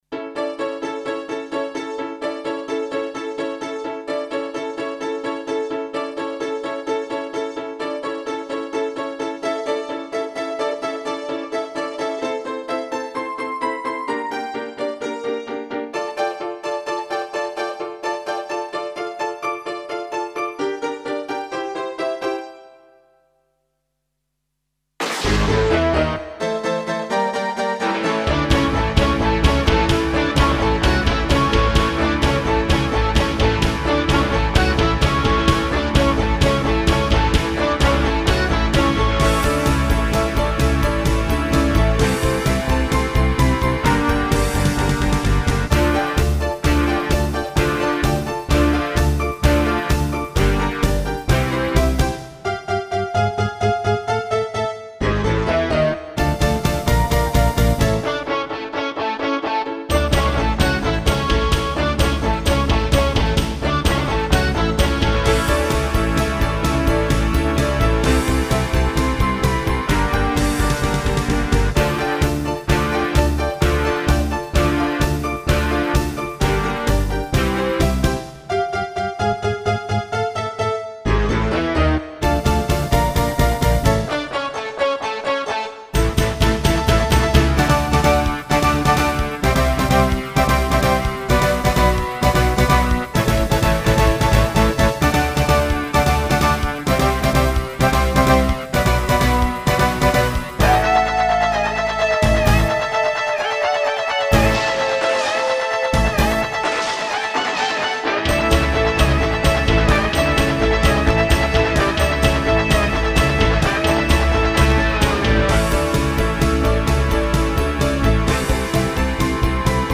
Selected sound: Acoustic Grand Piano
Selected sound: Acoustic Bass
Selected sound: Overdriven Guitar
Selected sound: Synth Voice
Selected sound: Guitar harmonics
Selected sound: Pizzicato Strings
Selected sound: Slap Bass 2
Selected sound: Drumset
Selected sound: Gunshot
Selected sound: Distortion Guitar